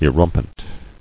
[e·rum·pent]